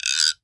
LGUIRO 4.wav